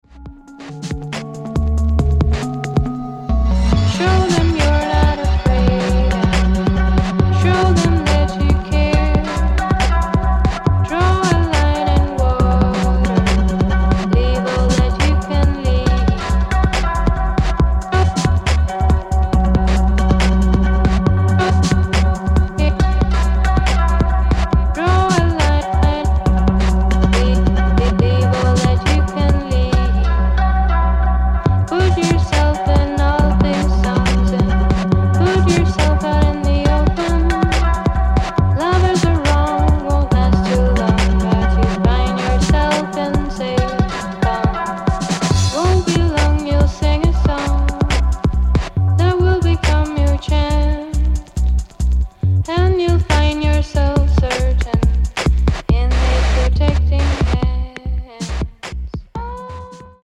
Finnish electronic music production team